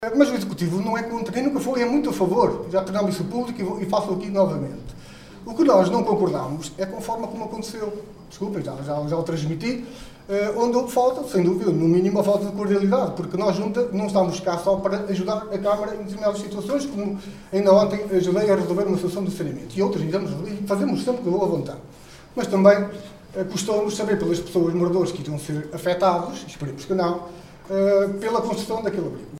Desta vez fê-lo olhos nos olhos com a maioria ‘laranja’, em reunião de câmara realizada no antigo edifício da outrora freguesia de Mazedo.
De voz firme, o presidente da Junta realçou ainda que de nada lhe teria adiantado ter estado presente na reunião de Câmara onde o assunto foi discutido.